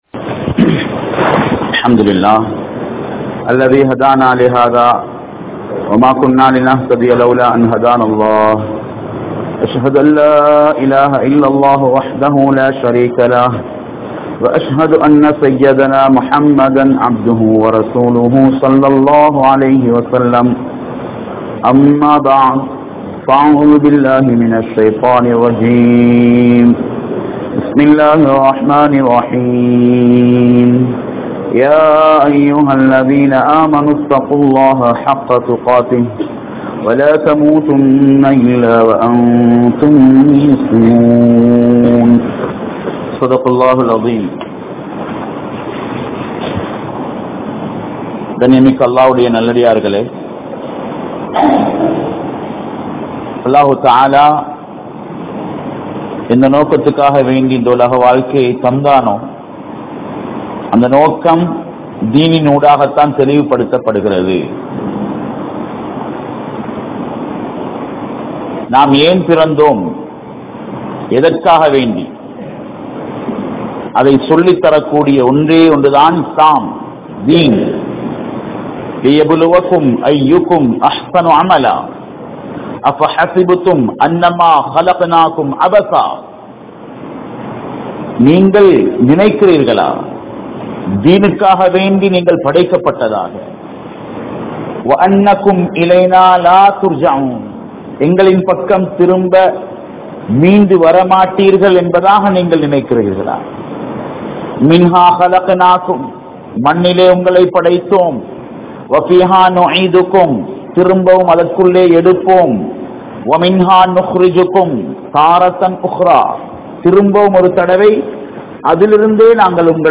Eemaan Illaatha Indraya Muslimkal (ஈமான் இல்லாத இன்றைய முஸ்லிம்கள்) | Audio Bayans | All Ceylon Muslim Youth Community | Addalaichenai